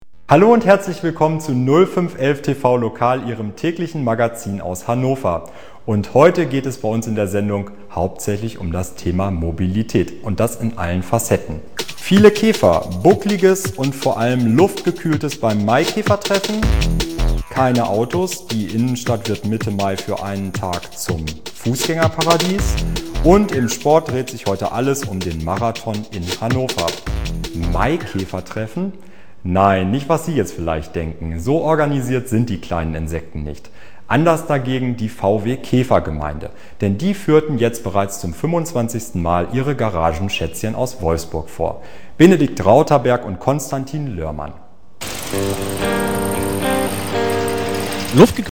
deutscher Sprecher
Kein Dialekt
Sprechprobe: Werbung (Muttersprache):
german voice over artist